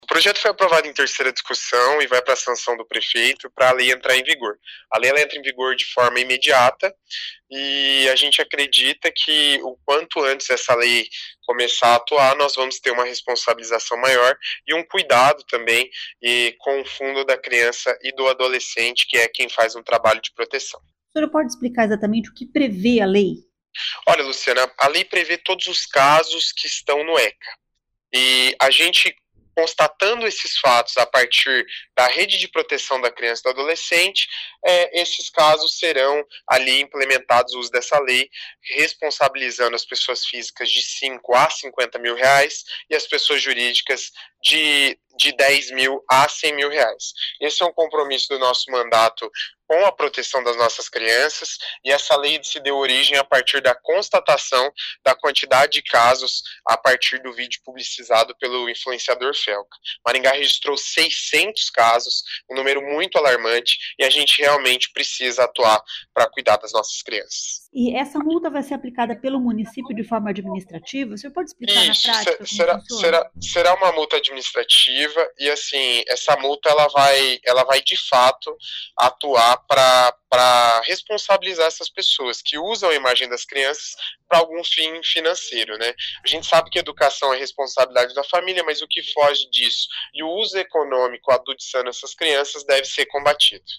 Ouça o que diz o vereador: